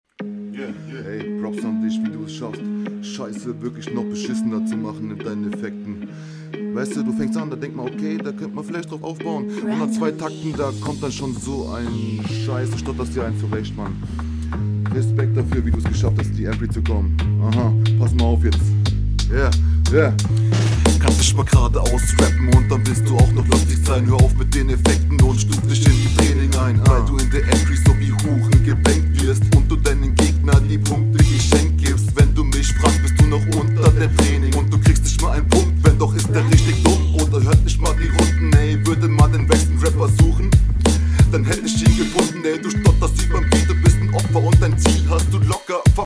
Stimmlich und raptechnisch zwar nicht grad gut, aber besser.
Intro ist nur Links, das mag ich nicht, stell es bitte in Mono Mittig, hzw …